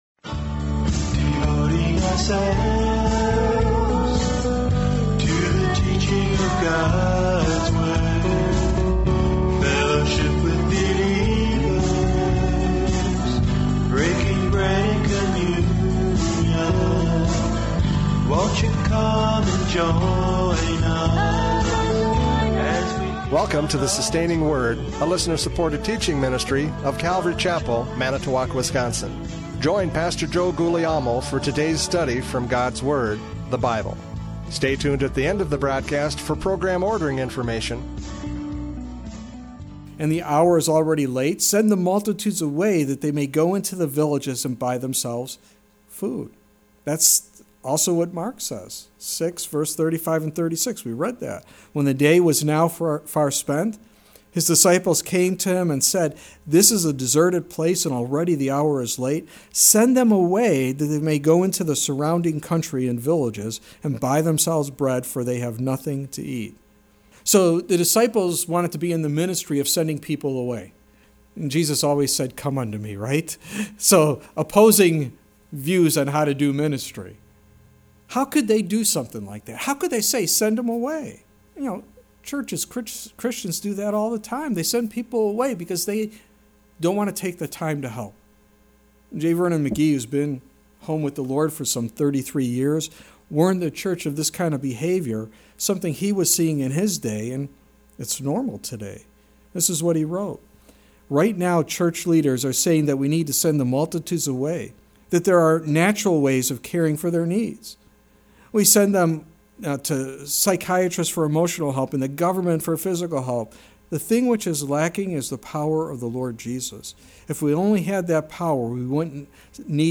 John 6:1-14 Service Type: Radio Programs « John 6:1-14 Spiritual Hunger!